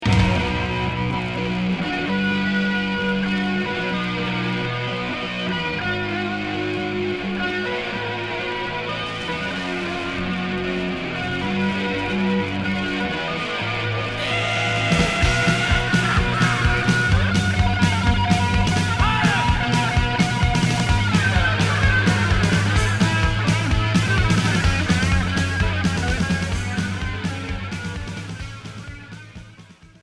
Rock hard Unique 45t retour à l'accueil